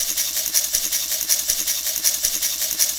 Shaker 02.wav